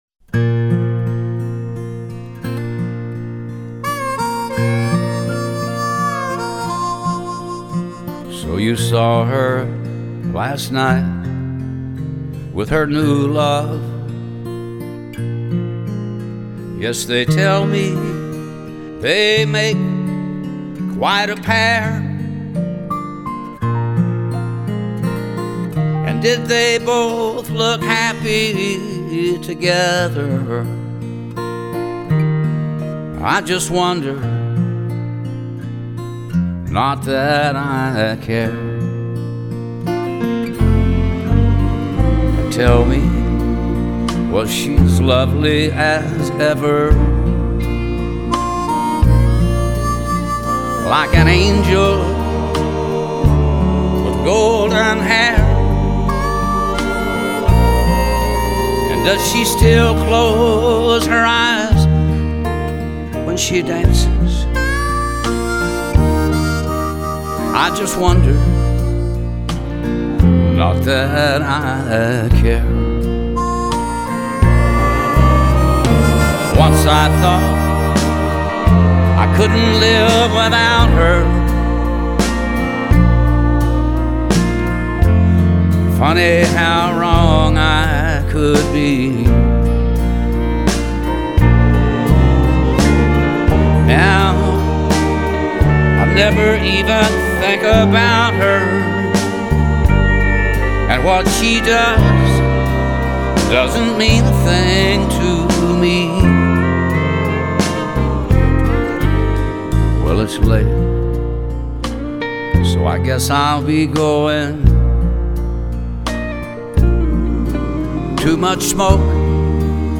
乡村歌曲
原野牛仔味道一傾而出